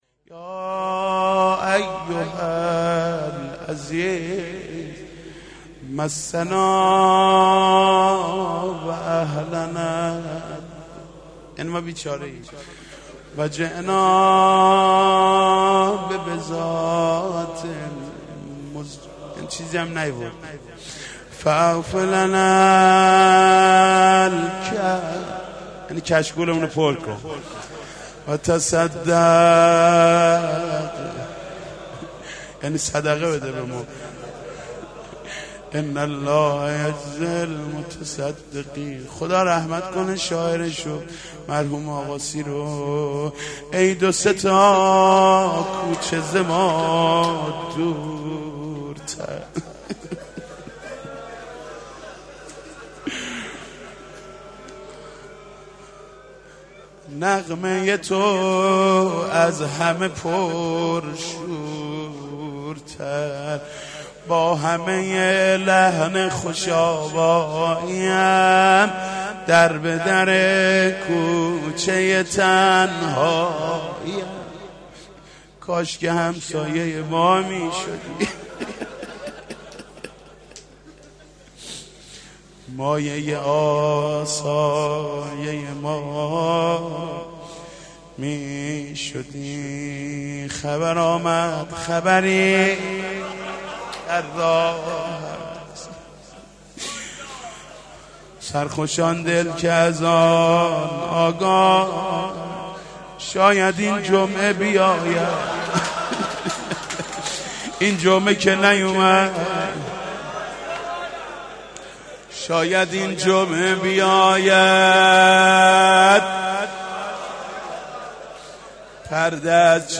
مناجات مهدوی